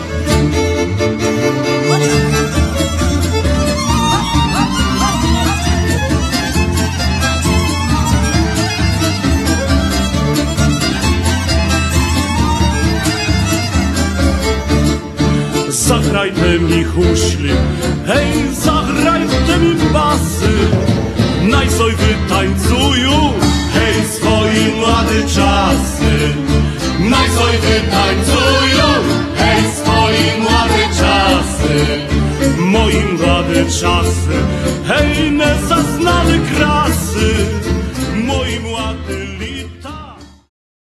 Zaprezentowali niezwykłe bogactwo brzmienia.
kontrabas